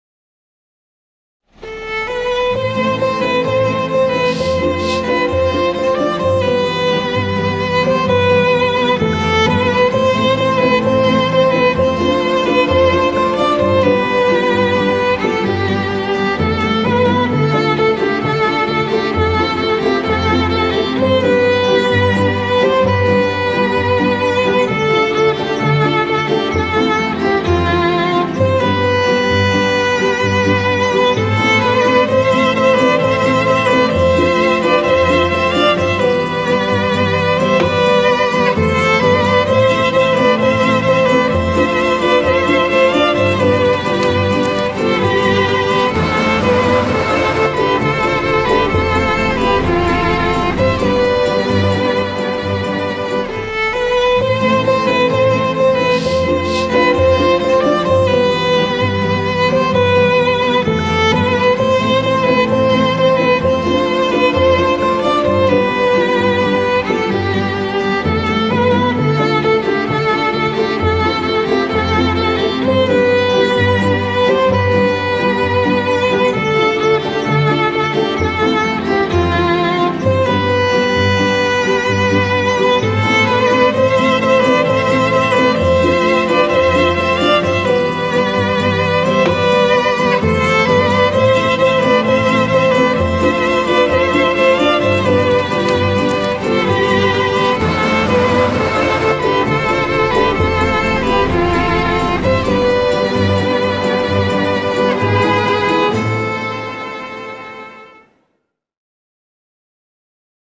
tema dizi müziği, mutlu neşeli eğlenceli fon müziği.